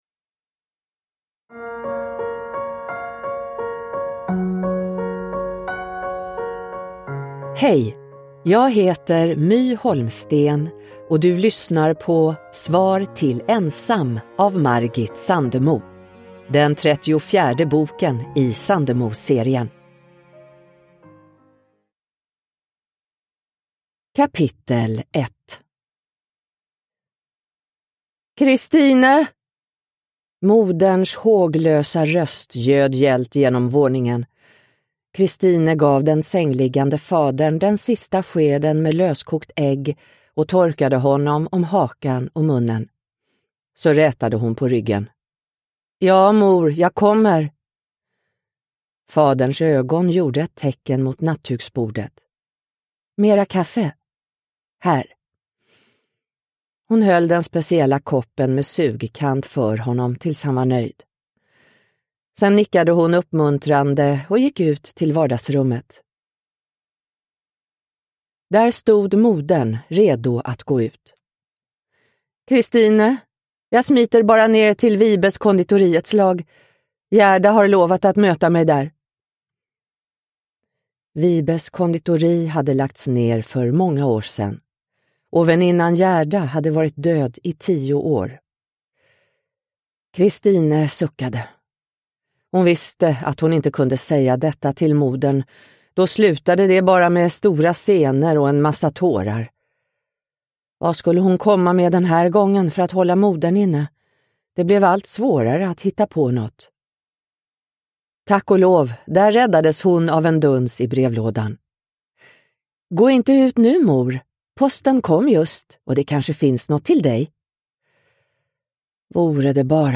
Svar till "ensam" – Ljudbok – Laddas ner
Produkttyp: Digitala böcker